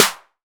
Tm8_Clap10.wav